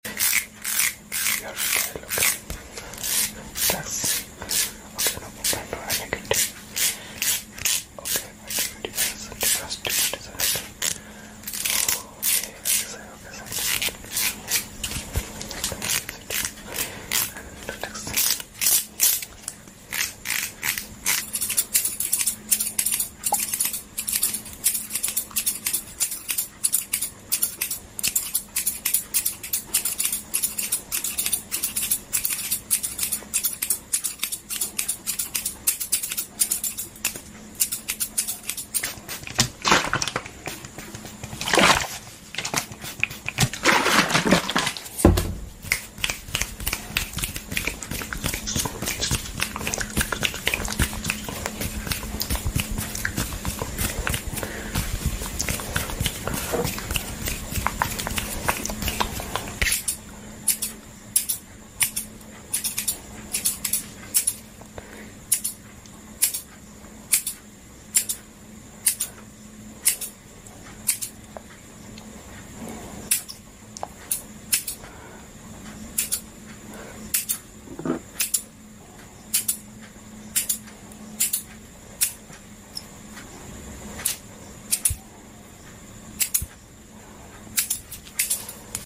Doing Your Hairstyle 🇮🇳✂ → sound effects free download